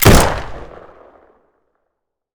pgs/Assets/Audio/Guns_Weapons/Guns/gun_rifle_shot_03.wav at master
gun_rifle_shot_03.wav